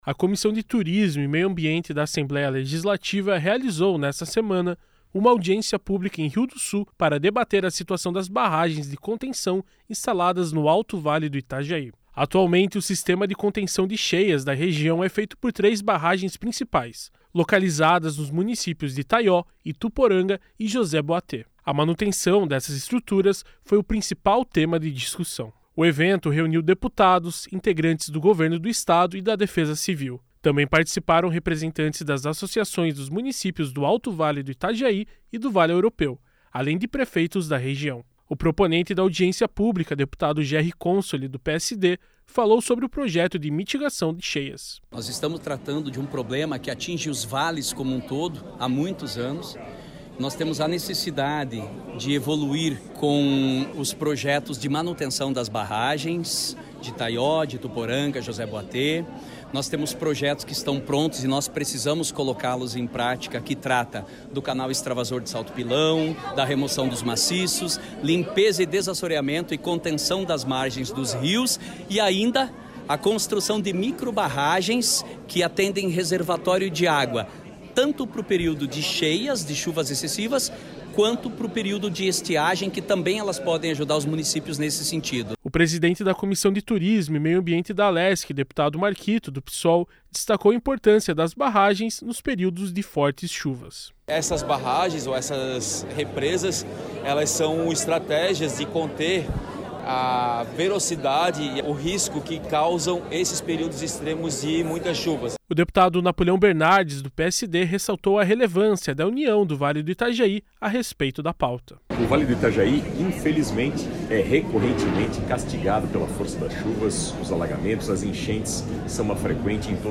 Entrevistas com:
- deputado Gerri Consoli (PSD), proponente da Audiência Pública;
- deputado Marquito (Psol), presidente da Comissão de Turismo e Meio Ambiente;
- José Thomé (PSD), prefeito de Rio do Sul;